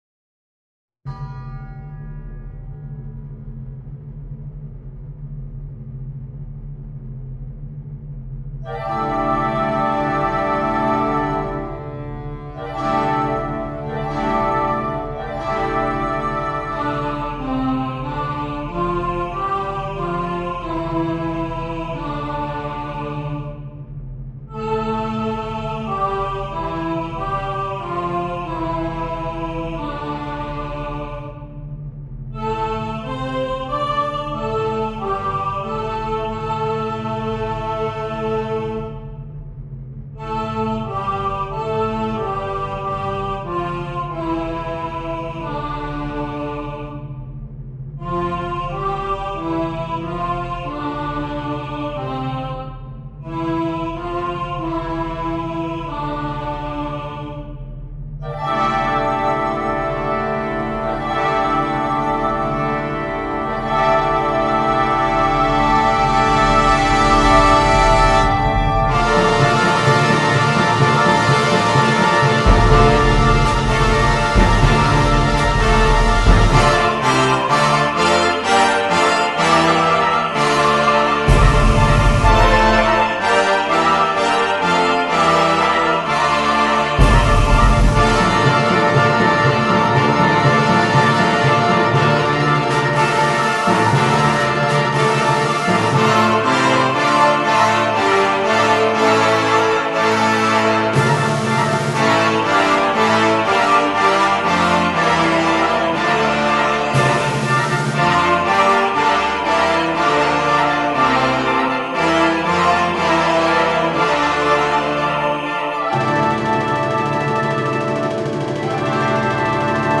per coro ad libitum e banda